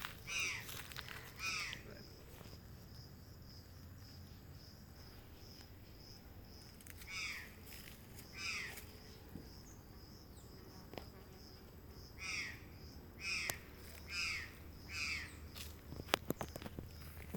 Urraca Campestre (Cyanocorax cristatellus)
Nombre en inglés: Curl-crested Jay
Localidad o área protegida: Posadas
Condición: Silvestre
Certeza: Fotografiada, Vocalización Grabada